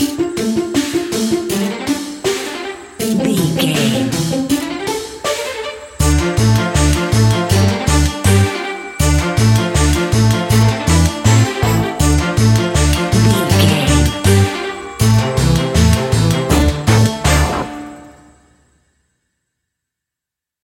Aeolian/Minor
tension
ominous
eerie
synthesiser
drum machine
instrumentals
horror music